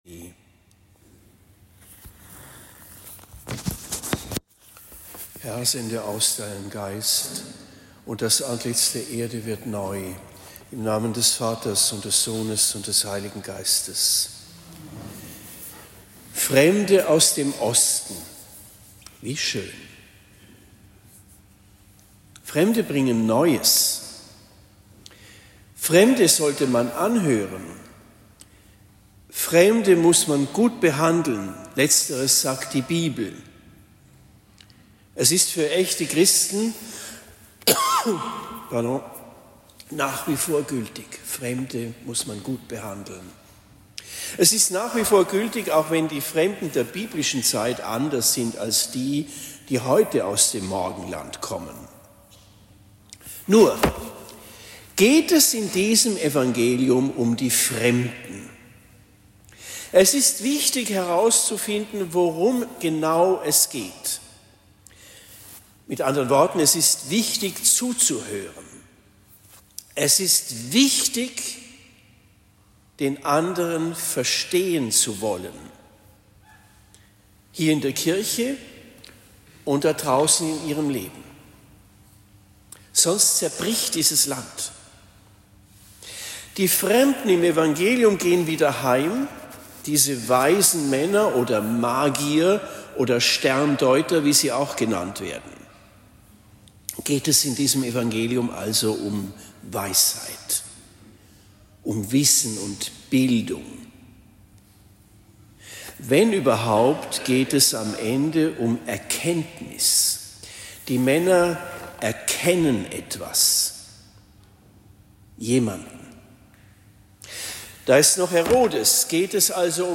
Predigt in Lengfurt am 06. Jänner 2026